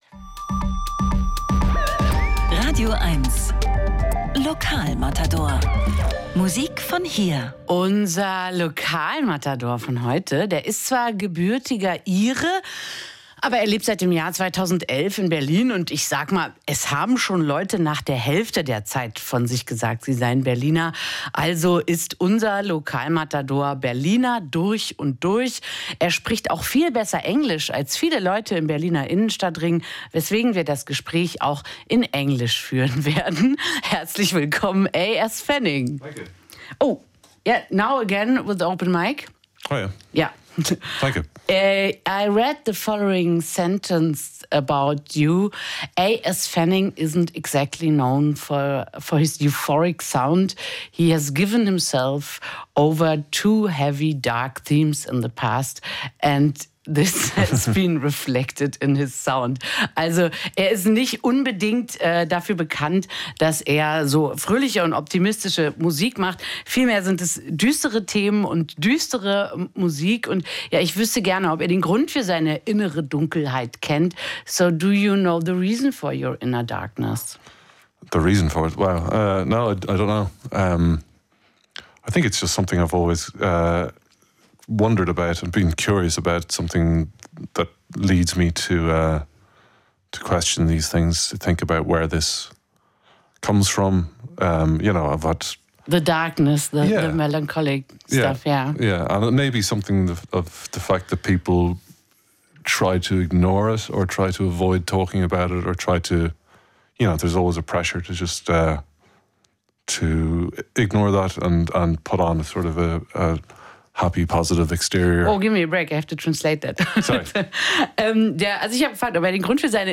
Musik-Interviews Podcast